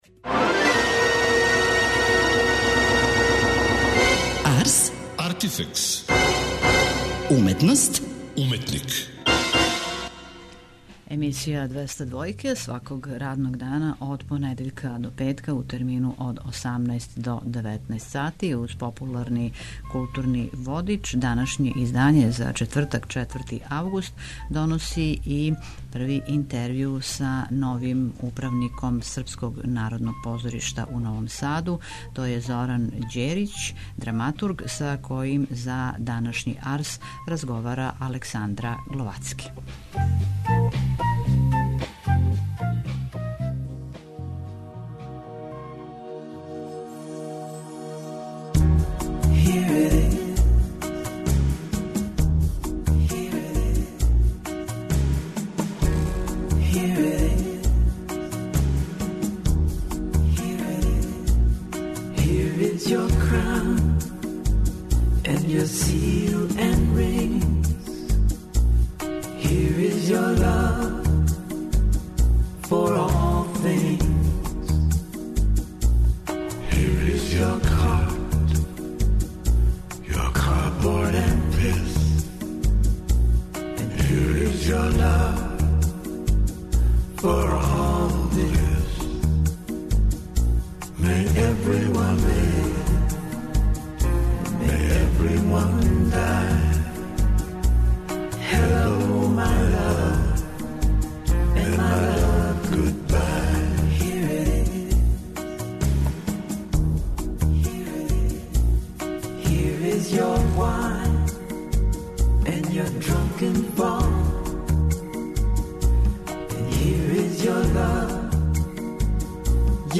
у разговору